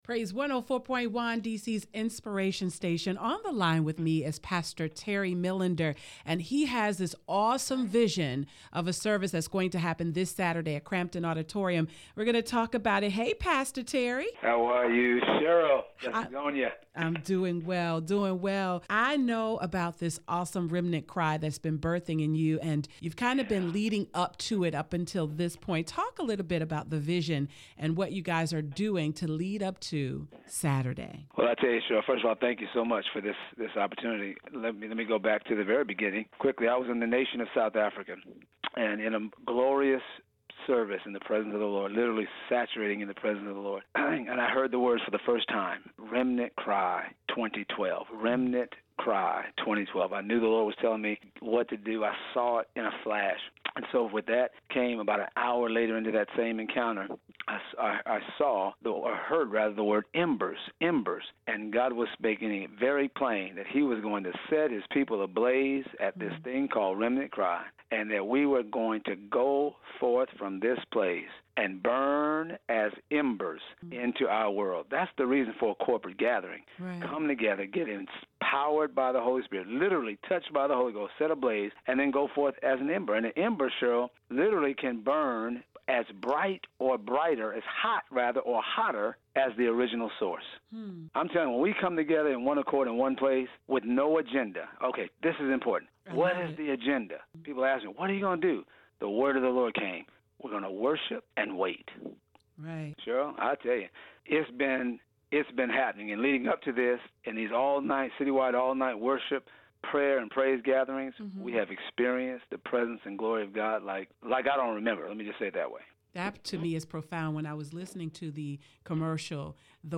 Remnant Cry 2012 (interview)